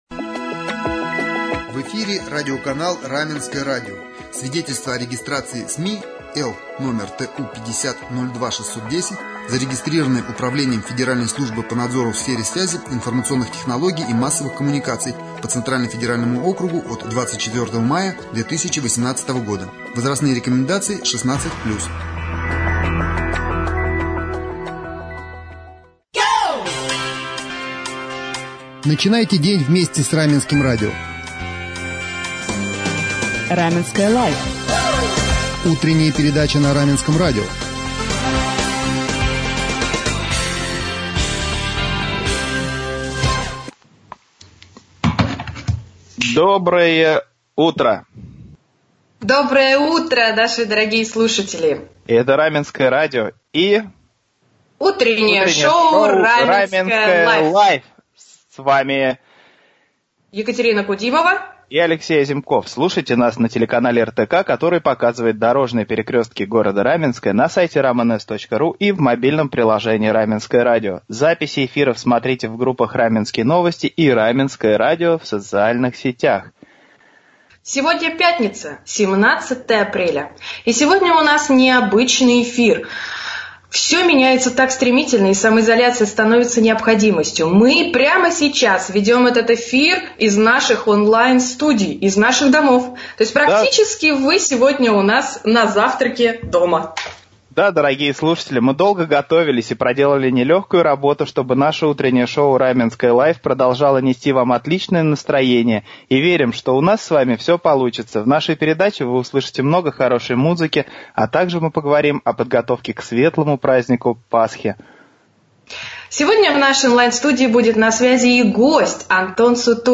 Поговорили об этом в пятницу 17 апреля в утренней передаче «Раменское Life».